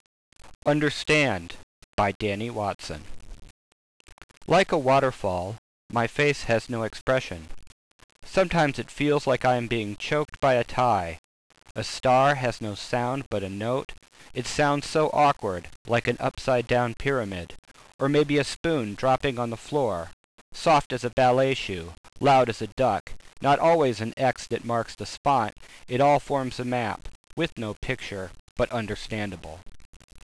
Or my dramatic reading of "